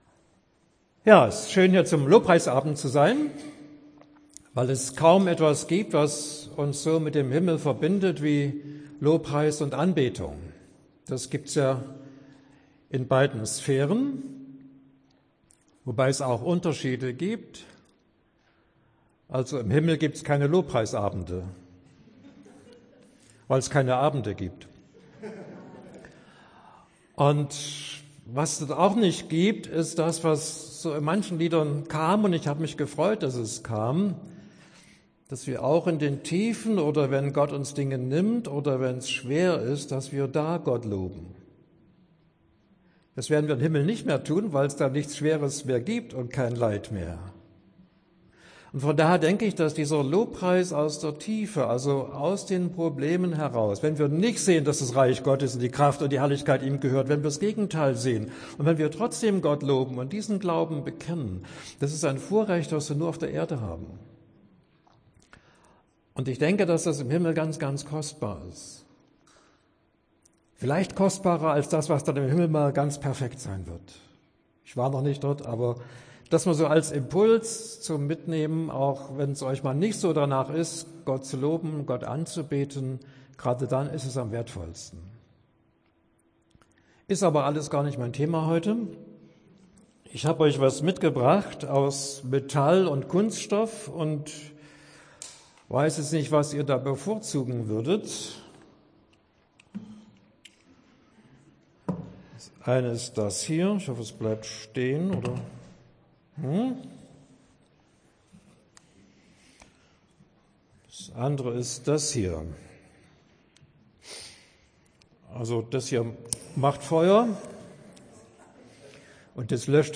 Predigt zum Lobpreisabend am Pfingstsonnabend